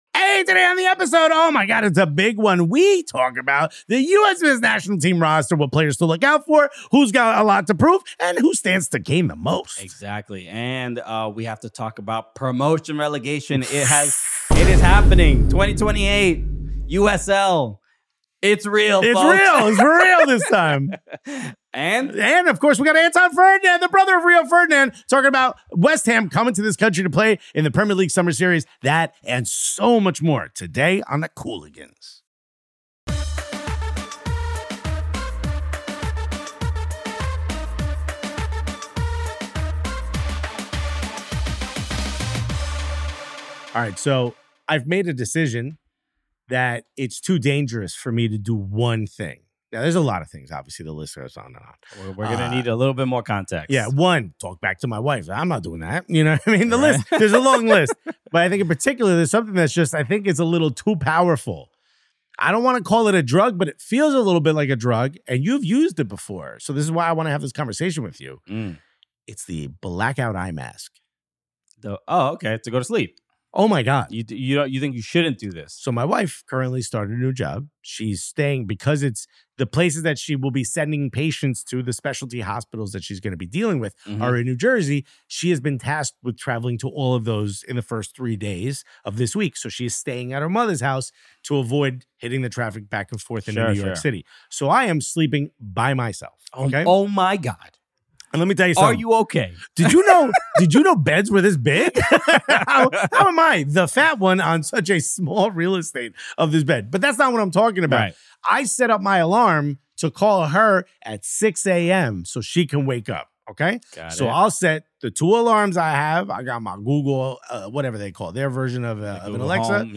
You’ll hear Marcelo Balboa reflect on the emotion of representing the U.S. at a home World Cup, Christian Pulisic explain his absence from the Gold Cup squad, and a fiery debate over whether Pochettino could squeeze big names out of the picture. Premier League legend Ashley Cole joins to break down Antonee Robinson, the modern fullback, and the USMNT’s World Cup chances, before Jürgen Klinsmann shares unforgettable stories involving Pulisic, Landon Donovan, and his lost World Cup-winning jersey.